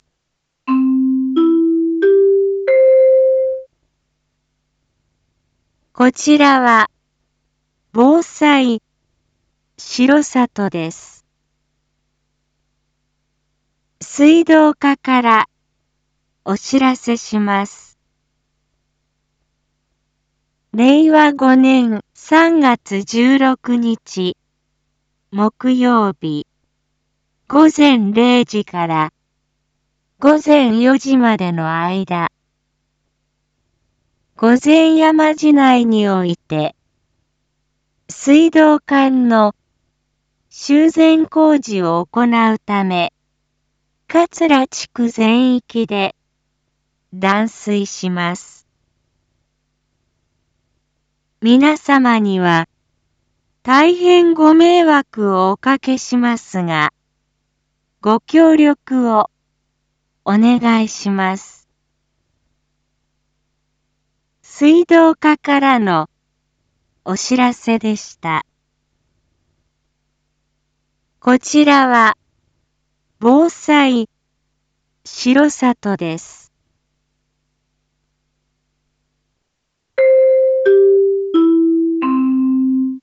Back Home 一般放送情報 音声放送 再生 一般放送情報 登録日時：2023-03-12 19:01:24 タイトル：水道断水のお知らせ（桂地区限定） インフォメーション：こちらは、防災しろさとです。